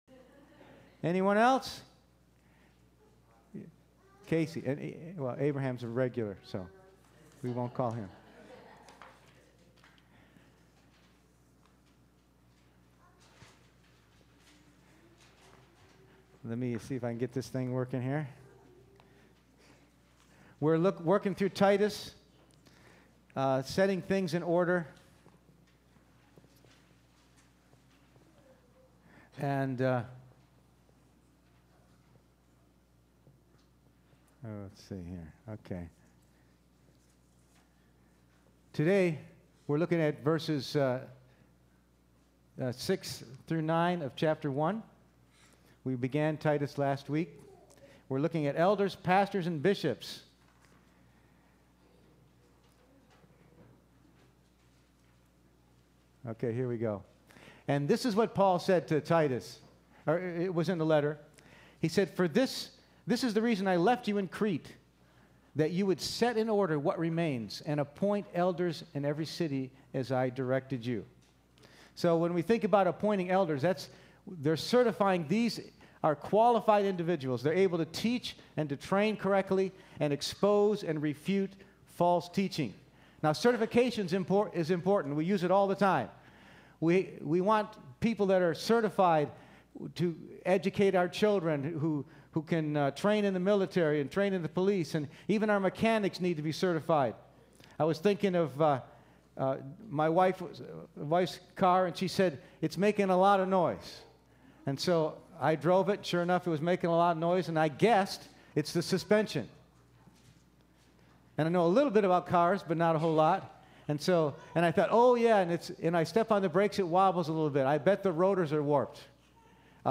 Passage: Titus 1:6-9 Service Type: Sunday Morning %todo_render% « God’s Chosen